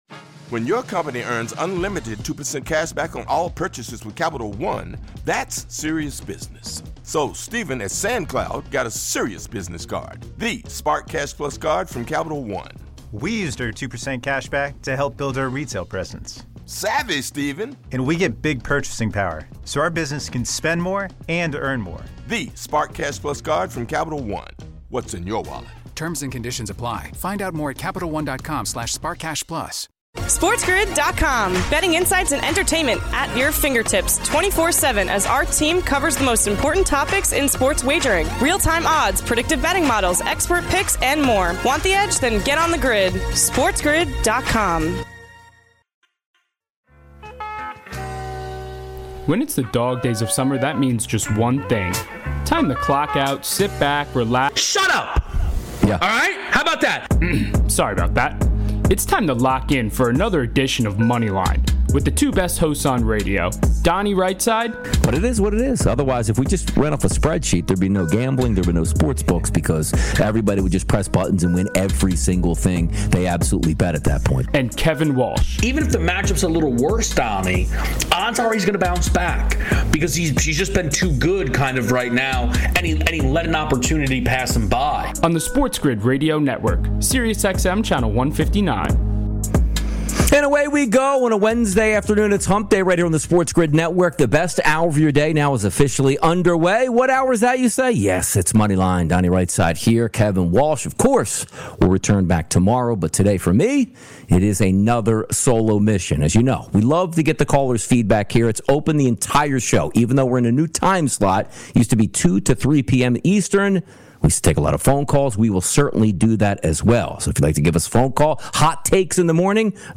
All this, your calls, and more!